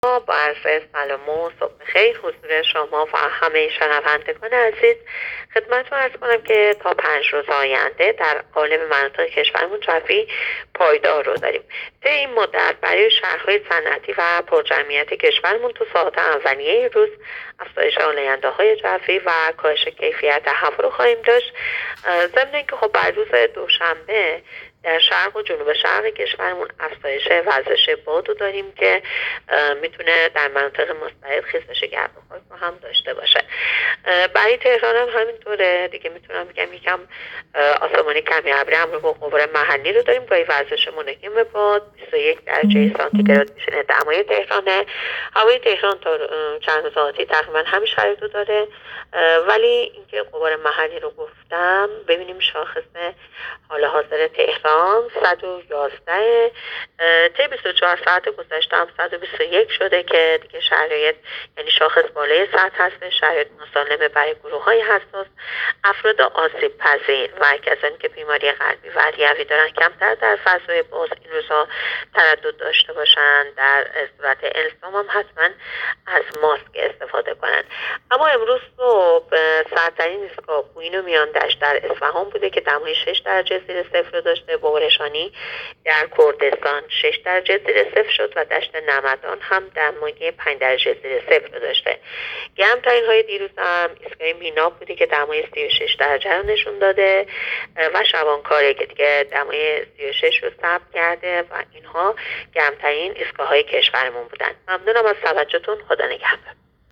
گزارش رادیو اینترنتی پایگاه‌ خبری از آخرین وضعیت آب‌وهوای ۱۷ آبان؛